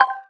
menu_click01.wav